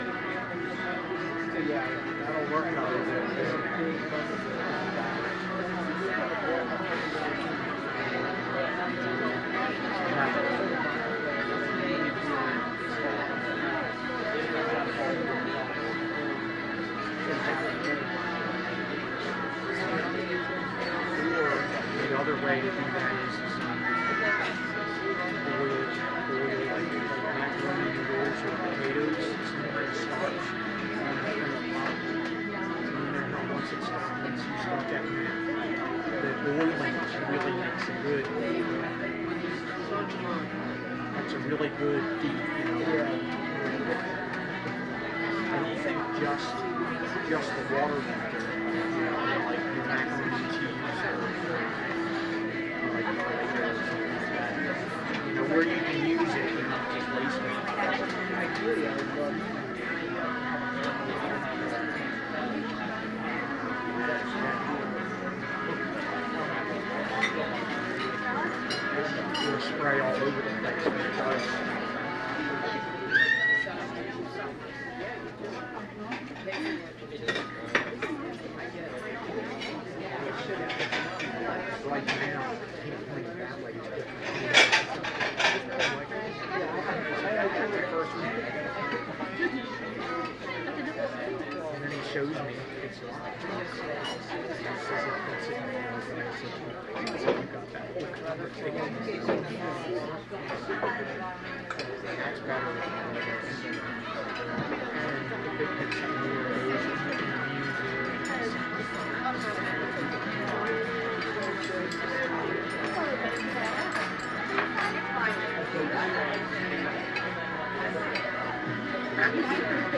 酒吧餐厅打烊，喋喋不休，眼镜，瓦拉，中等成人杂音
描述：纽约市夜总会在凌晨4点关闭，人群沃拉，保镖，厨房工作人员
Tag: 眼镜 喋喋不休 关闭 杂音 成人 聊天 餐厅 人群 酒吧 媒体 沃拉